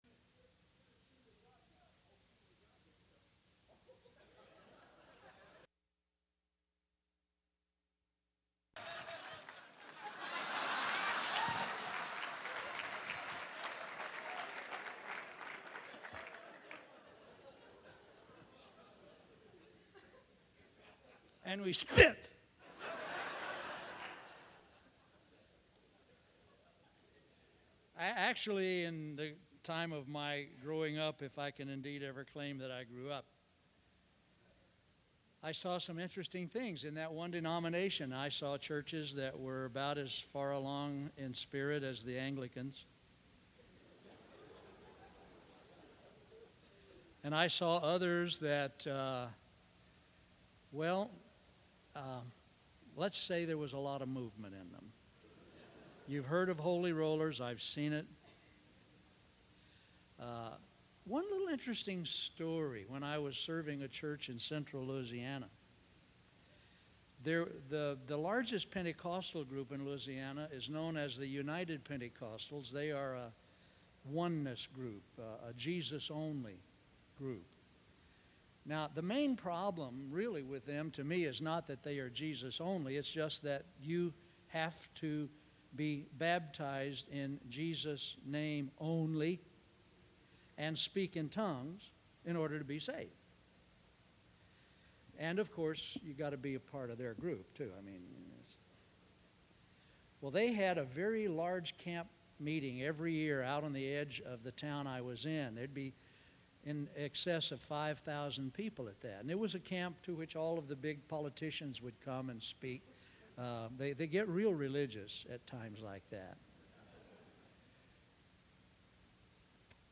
2009 Home » Sermons » Session 3 Share Facebook Twitter LinkedIn Email Topics